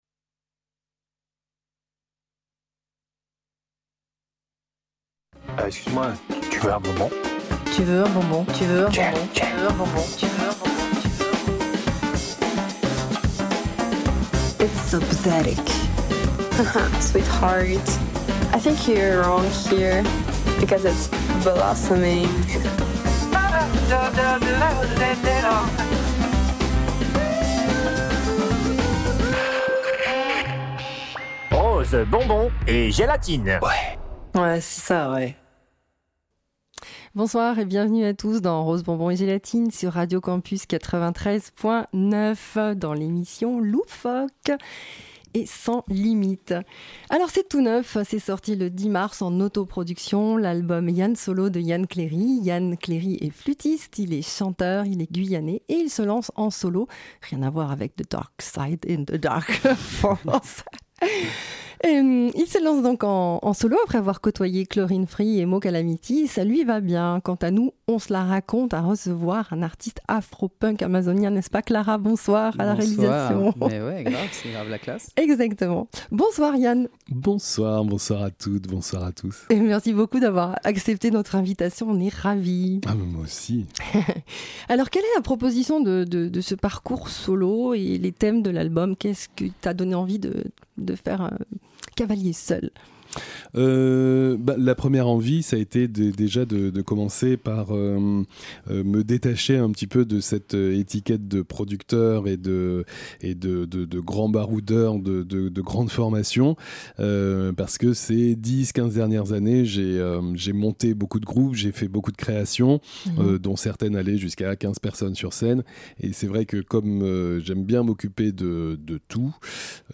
INTERVIEW & PLAYLIST ÉMISSION du 28/04/2023 // Artiste – Album – Titre – Label